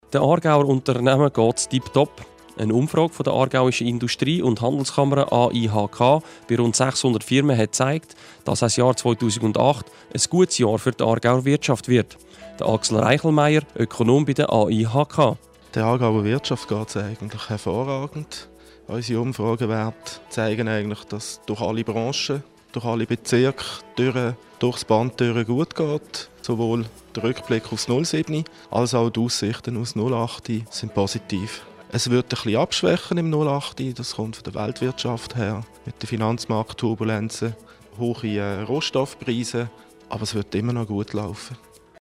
Interview zur AIHK-Wirtschaftsumfrage 2008